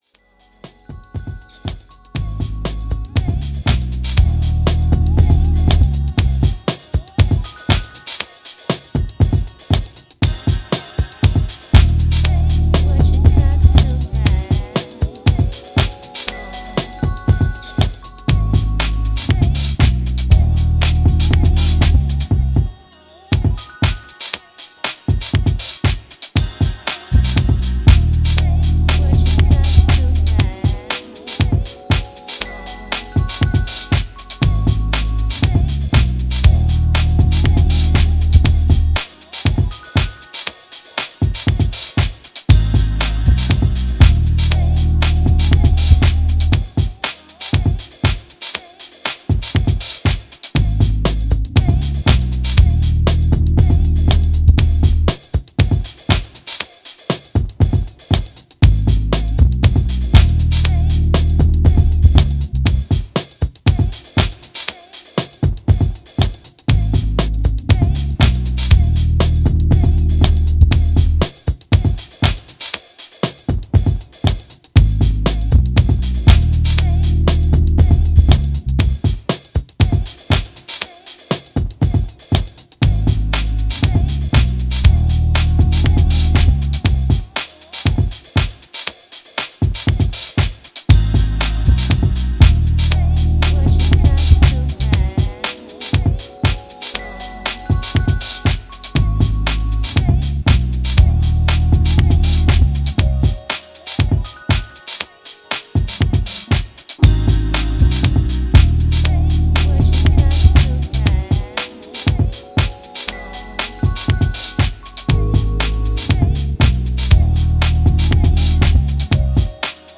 Outside-the-box dreamy techno tracks.